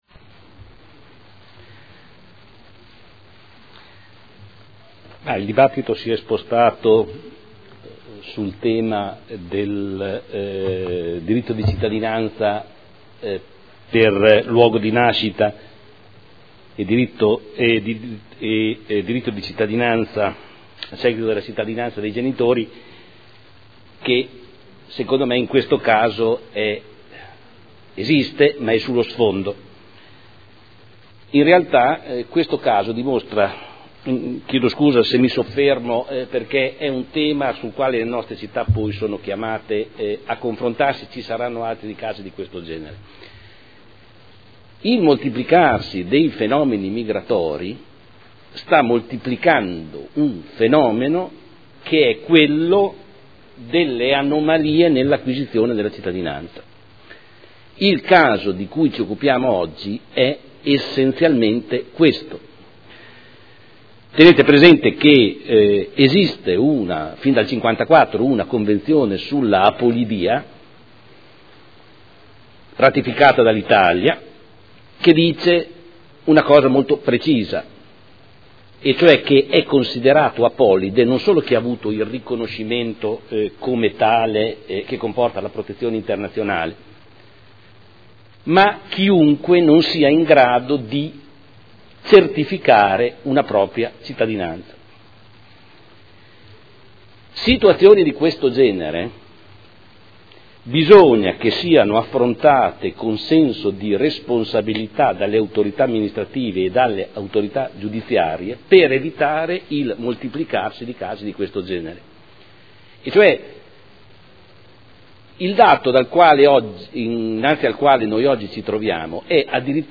Seduta del 03/05/2012.